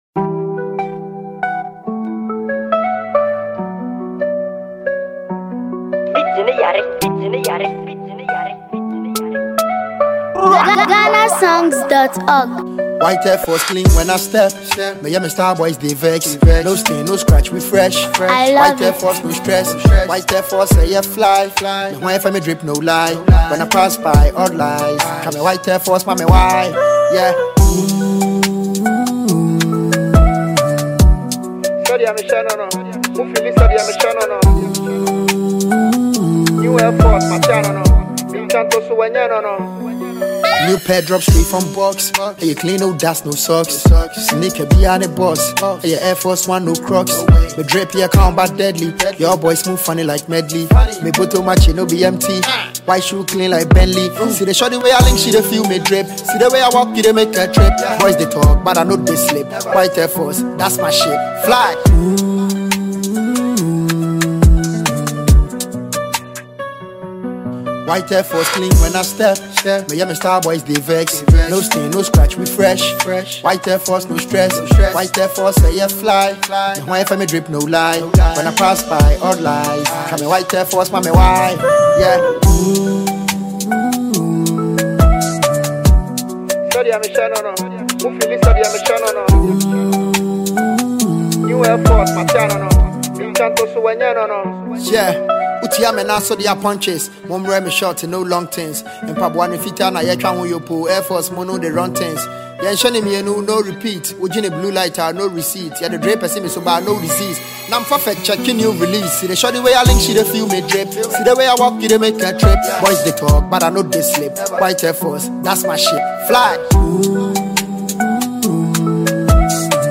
drill anthem